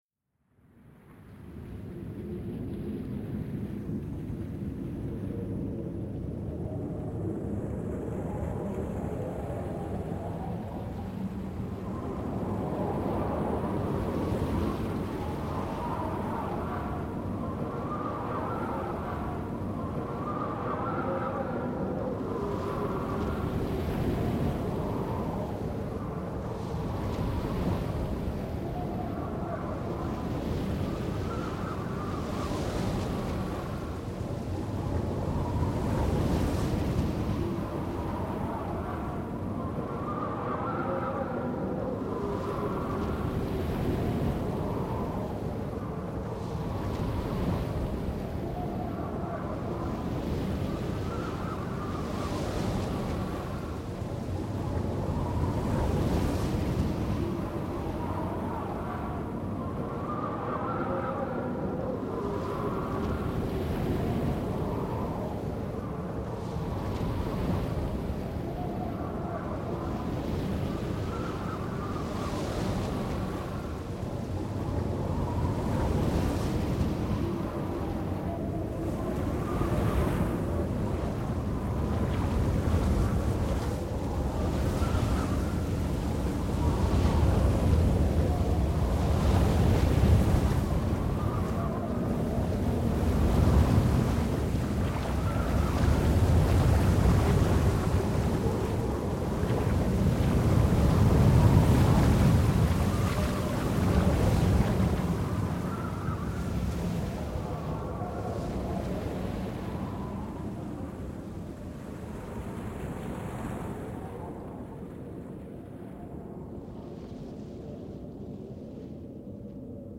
Звуки водоворота
Шум вращающейся воды